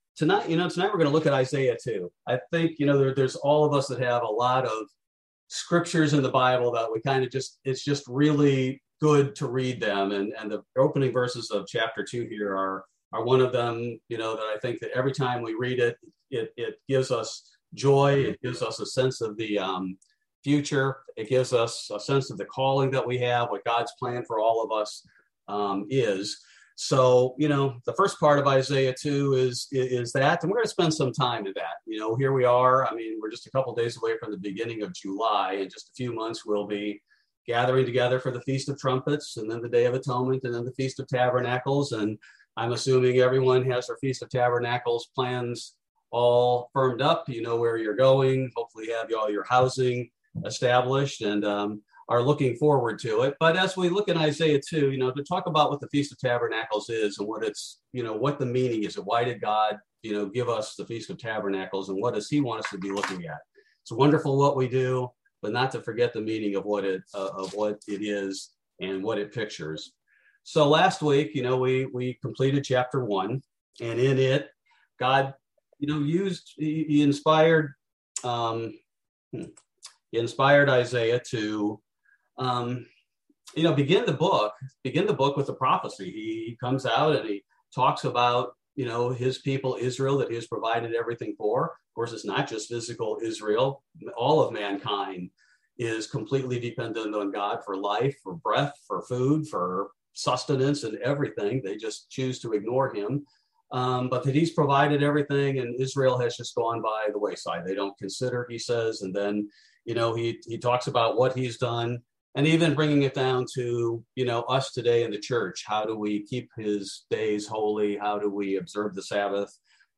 Bible Study: June 29, 2022